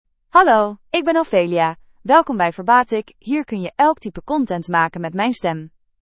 Ophelia — Female Dutch AI voice
Ophelia is a female AI voice for Dutch (Netherlands).
Voice sample
Listen to Ophelia's female Dutch voice.
Ophelia delivers clear pronunciation with authentic Netherlands Dutch intonation, making your content sound professionally produced.